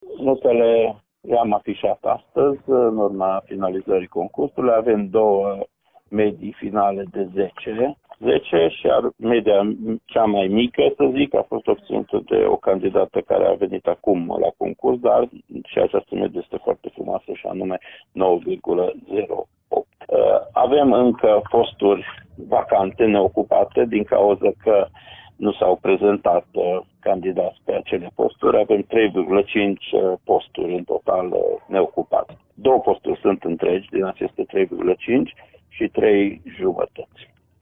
Pe lista notelor finale au fost și note de 10, a explicat inspectorul școlar general al județului Mureș, Ștefan Someșan.